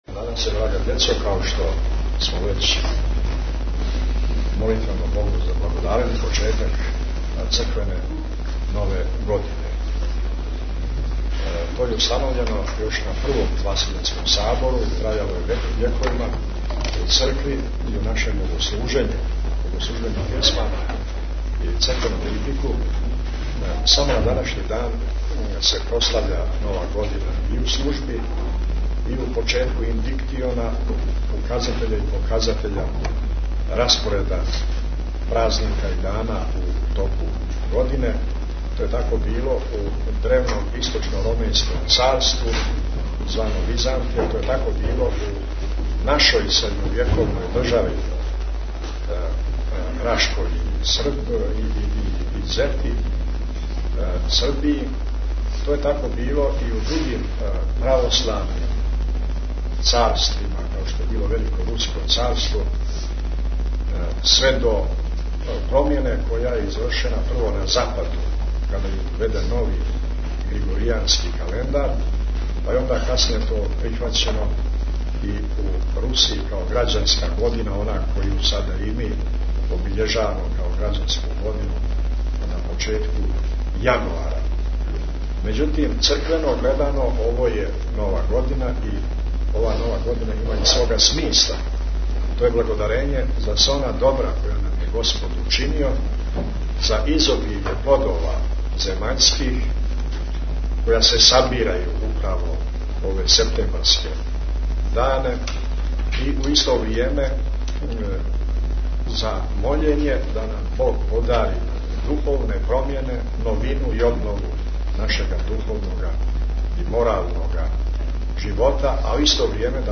Бесједа Митрополита Амфилохија на дан почетка Црквене нове године у Цетињском манастиру
Г. Амфилохија са Свете Архијерејске Литургије и службе благодарења за почетак Црквене нове године коју је у петак 14. септембра, са свештенством, служио у Цетињском манастиру.